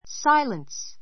silence A2 sáiləns サ イれン ス 名詞 しゃべらないこと, 沈黙 ちんもく , 無口; 音のしないこと, 静けさ Silence, please!